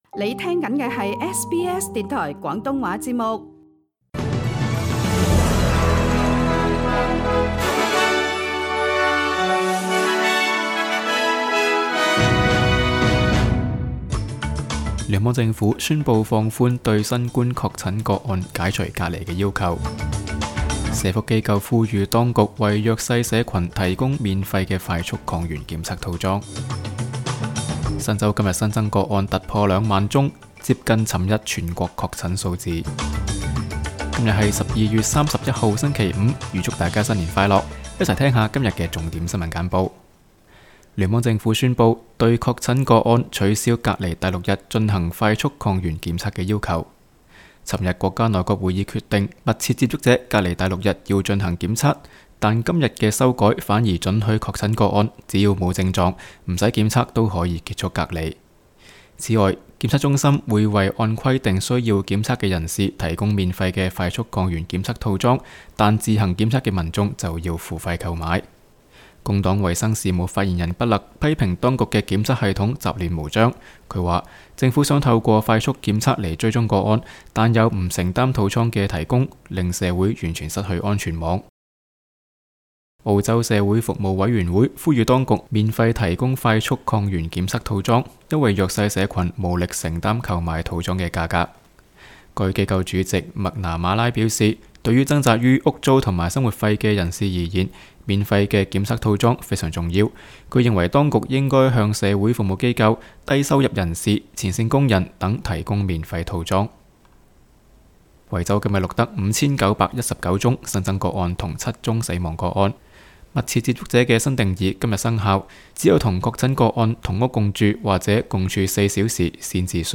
SBS 新聞簡報（12月31日）
請收聽本台為大家準備的每日重點新聞簡報。